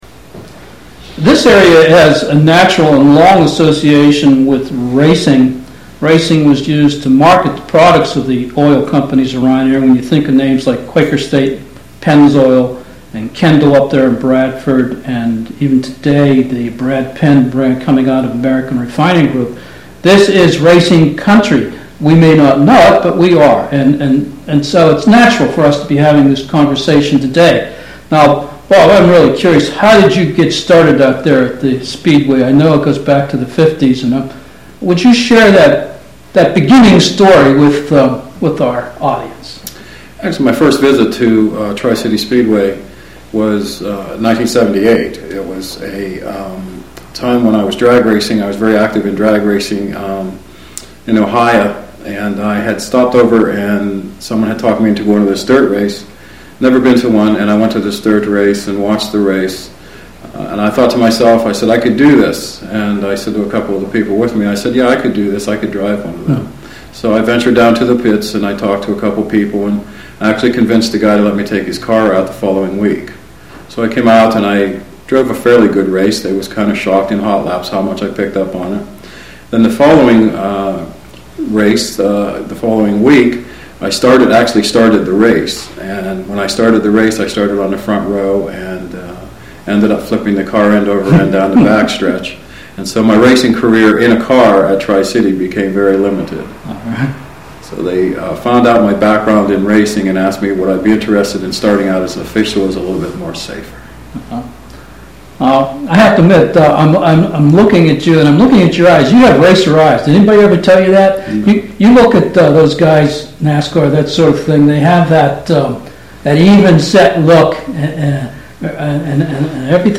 2009-09-11 Bob Graham Interview Audio File | KLN Islandora